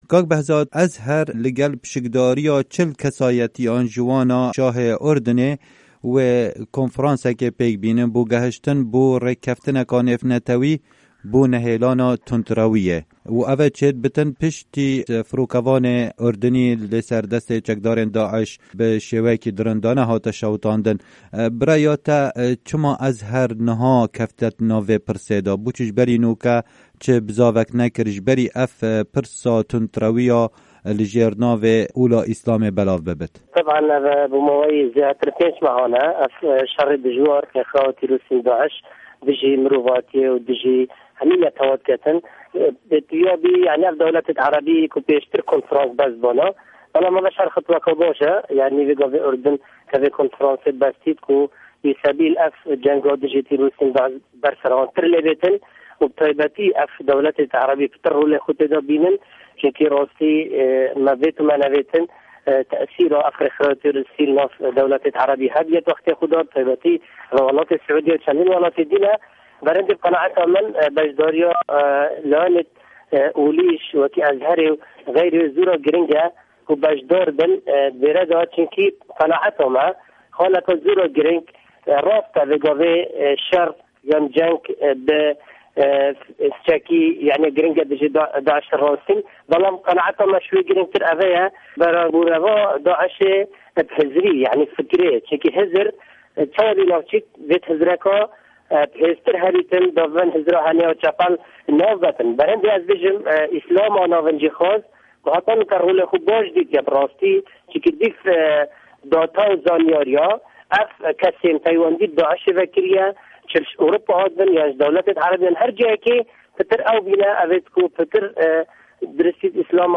Di hevpeyvînekê de ligel Dengê Amerîka, endamê Perlamanî Herêma Kurdistanê li ser lista Yekgirtû Îslamî Behzad Derwîş dibêje, ev cure konferans zor girîng in jibo şikandina terorê û nehêlana hizra tund yên ku ji têgehên Îslamê dûr in.
Hevpeyvin digel Bezad Derwîş